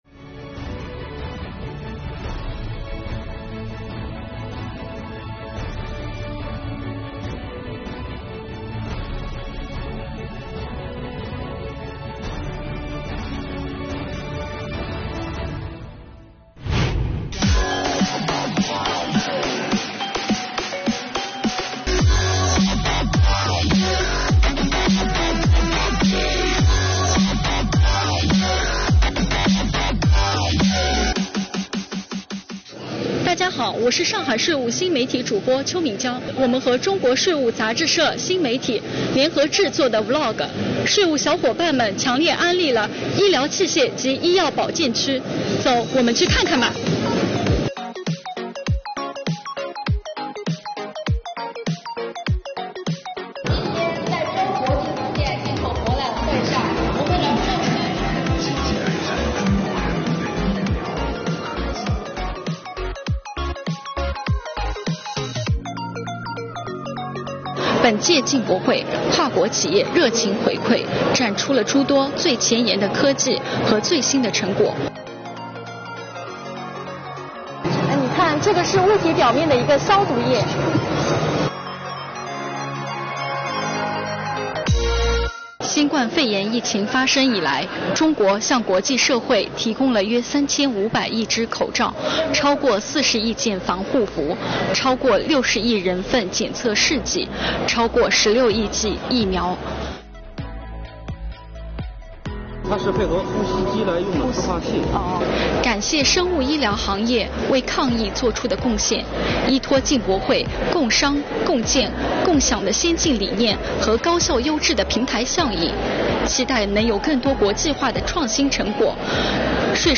我们的主播走进展厅，带大家来定格进博会上的精彩瞬间。
接下来，主播继续带大家来到医疗器械及医药保健展区。